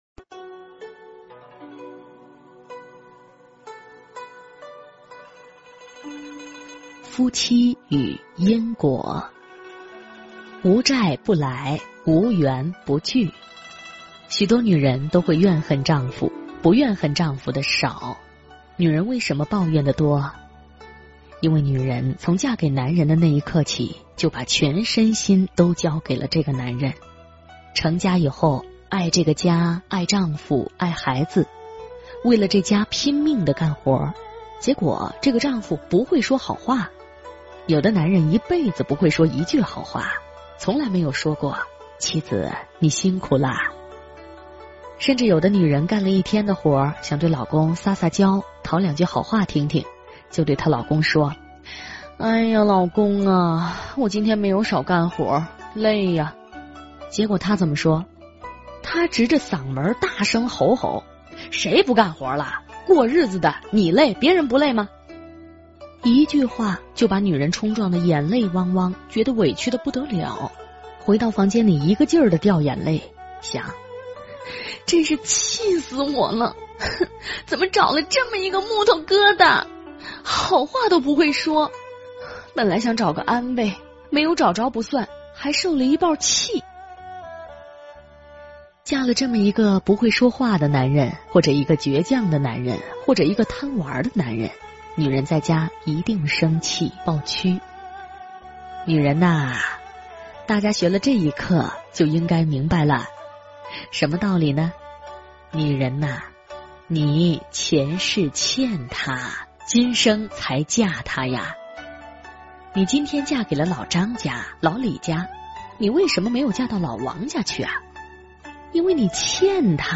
夫妻与因果--有声佛书